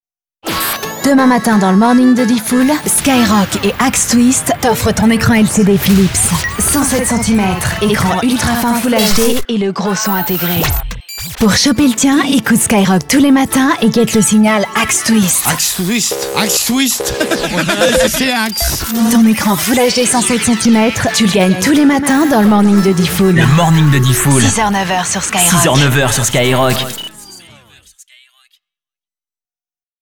Comédienne et chanteuse,je fais des voix régulièrement (doublage,pub,habillage radio et TV) parallèlement à mes activités sur scène.
Sprechprobe: Industrie (Muttersprache):